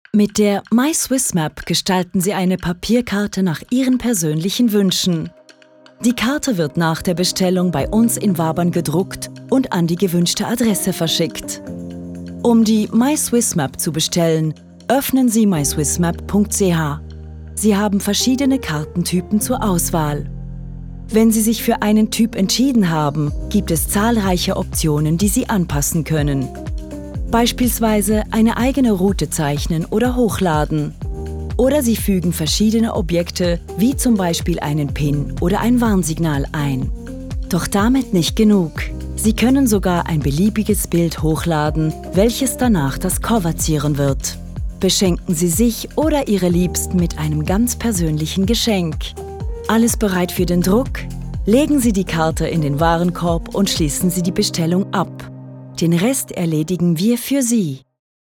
Sprecherin mit breitem Einsatzspektrum.